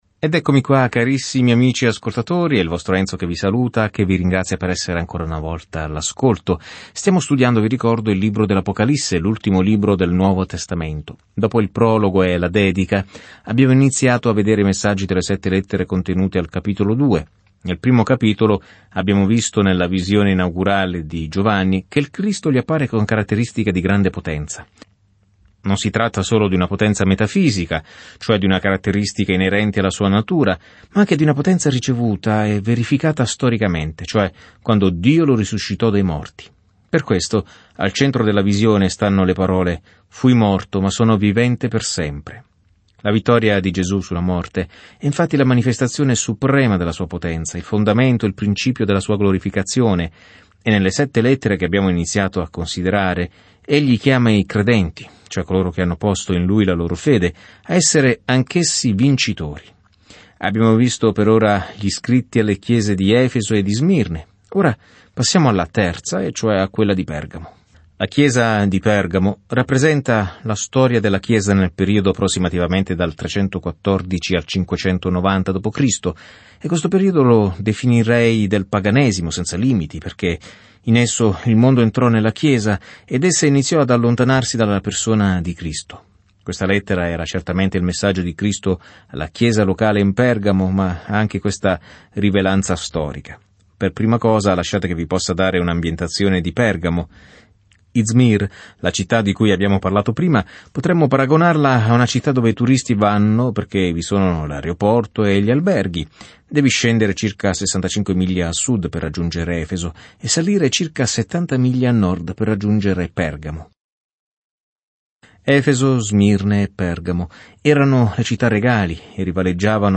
Scrittura Apocalisse di Giovanni 2:12-13 Giorno 8 Inizia questo Piano Giorno 10 Riguardo questo Piano L’Apocalisse registra la fine dell’ampia linea temporale della storia con l’immagine di come il male verrà finalmente affrontato e il Signore Gesù Cristo governerà con ogni autorità, potere, bellezza e gloria. Viaggia ogni giorno attraverso l'Apocalisse mentre ascolti lo studio audio e leggi versetti selezionati della parola di Dio.